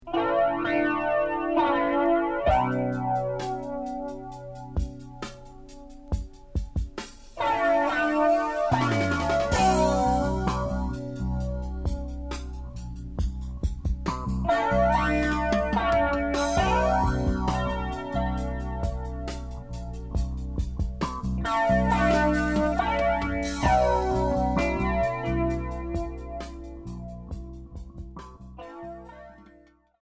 Cold wave Unique 45t retour à l'accueil